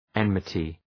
Προφορά
{‘enmətı}
enmity.mp3